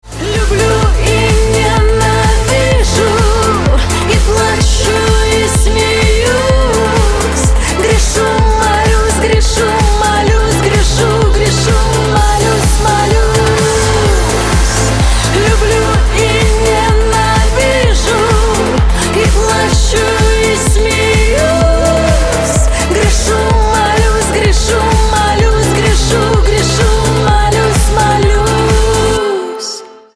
• Качество: 128, Stereo
поп
dance